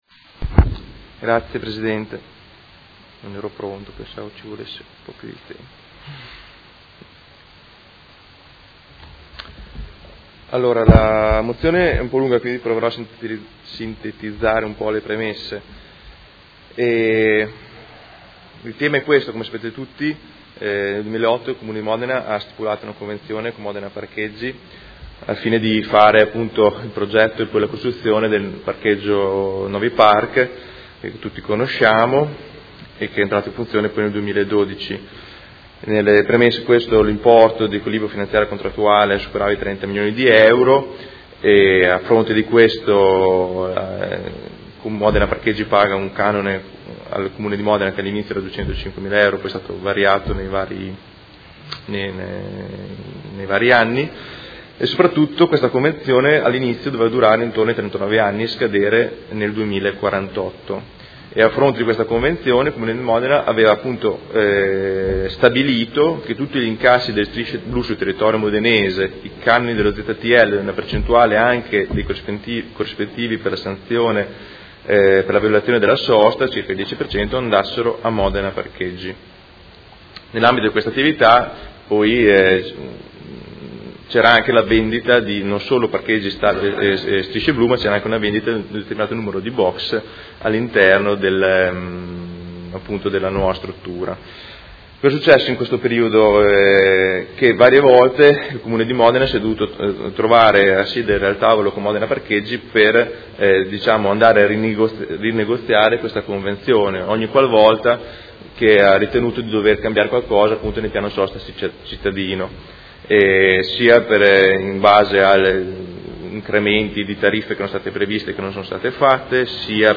Seduta del 20/12/2018. Presenta mozione Prot. Gen. 207654